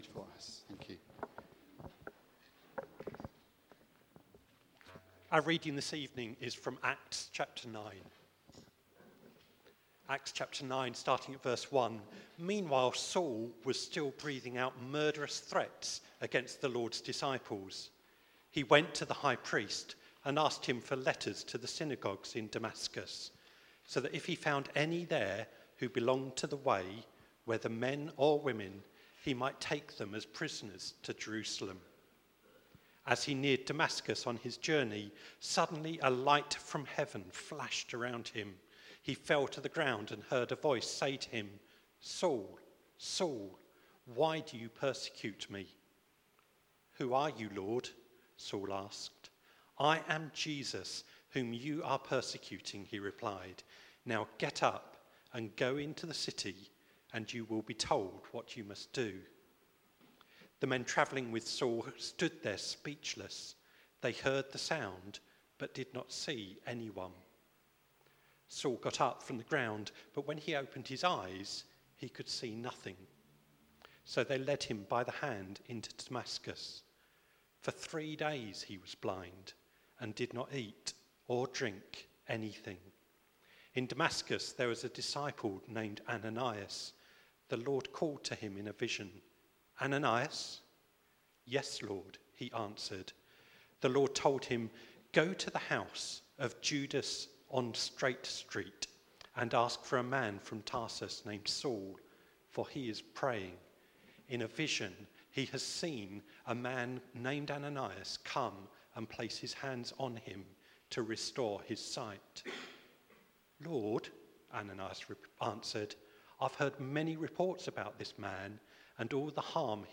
A message from the series "Praise & Prayer."